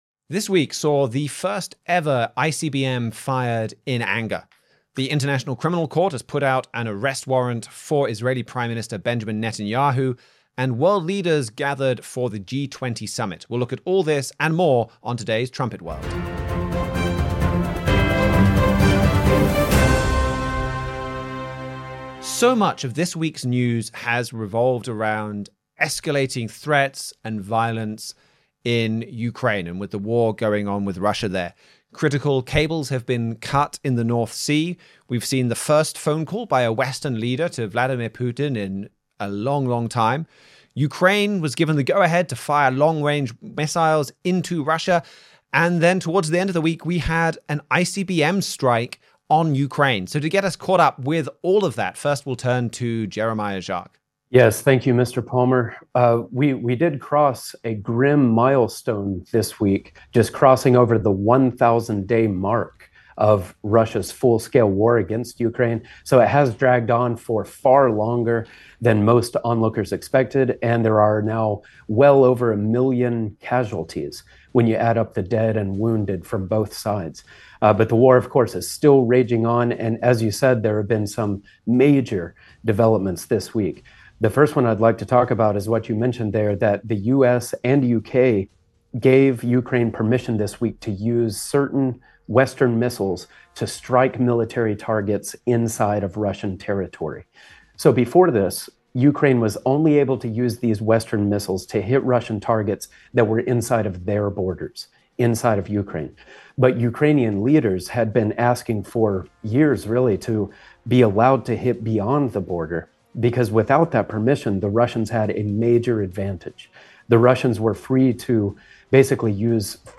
“ America Is Being Besieged Economically ” 39:28 ICC Slams the Gavel on Bibi We went live when the news broke regarding the International Criminal Court issuing arrest warrants for Israeli Prime Minister Benjamin Netanyahu and former Defense Minister Yoav Gallant.